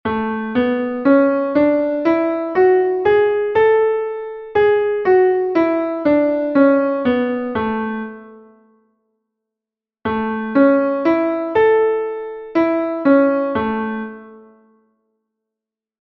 Entoación a capella
escala_arpegio_la_M.mp3